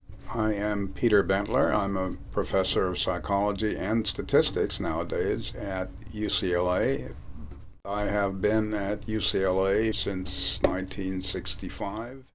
Dr. Bentler introduces himself: